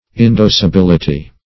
Search Result for " indocibility" : The Collaborative International Dictionary of English v.0.48: Indocibility \In*doc`i*bil"i*ty\, n. The state of being indocible; indocibleness; indocility.